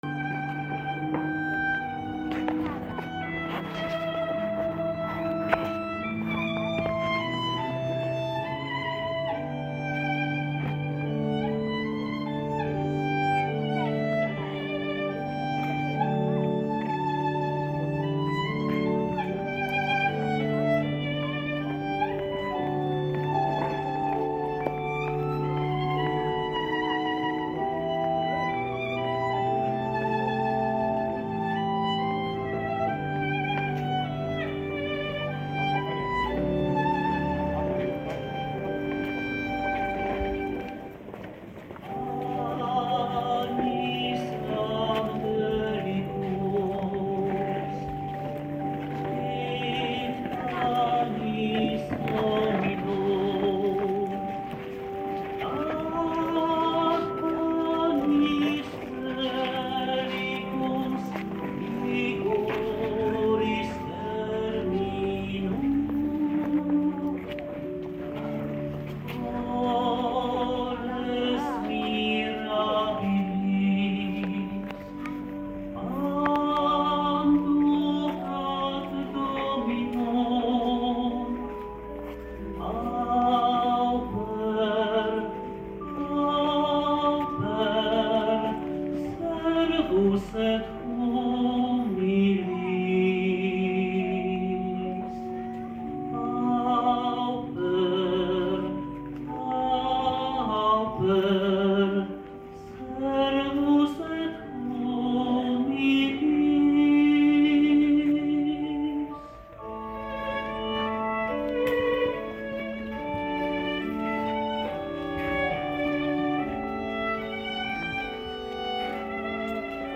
Eucharystia, która sprawowana była na ołtarzu polowym, pogoda dopisała, tylko lekki, krótki, deszczyk na zakończenie.
na organach
Chór Męski podczas Komunii św.